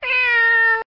Minish Meow Bouton sonore